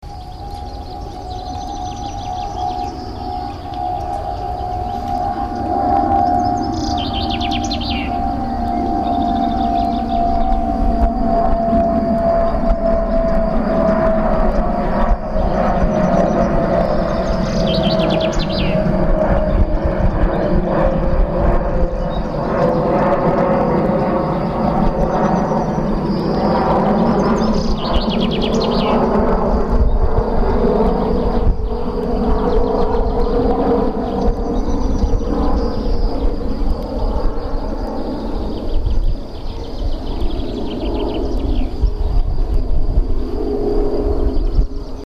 Le bruit enregistré, en automatique avec un simple micro d’ambiance
Passage à Kussaberg 2, juin 2005